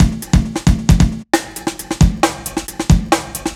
Funky Break 2 135.wav